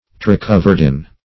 Search Result for " turacoverdin" : The Collaborative International Dictionary of English v.0.48: Turacoverdin \Tu*ra`co*ver"din\, n. [See Turacou , and Verdant .]
turacoverdin.mp3